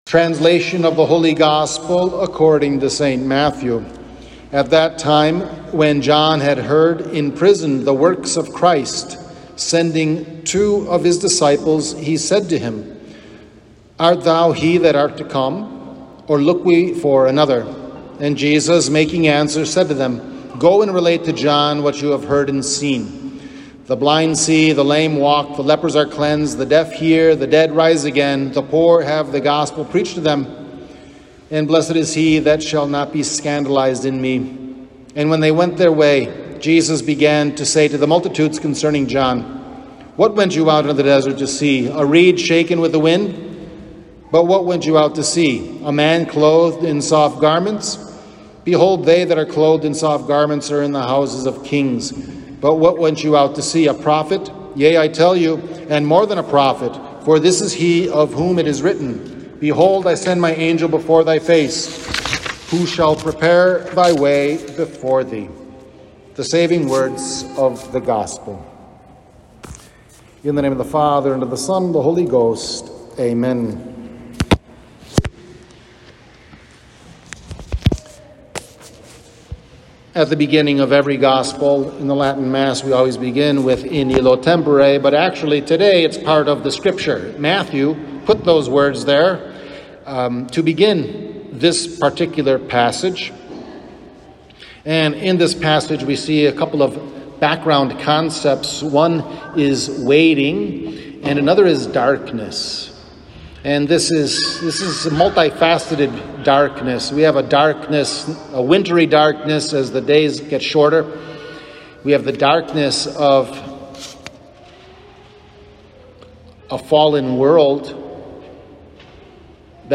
Transcription of Homily